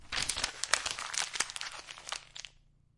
碎纸片
描述：在手里面被弄皱的常规打印机纸片断。立体图像录制。
Tag: 揉碎 折叠 页面 滚动 揉皱 沙沙 沙沙 压皱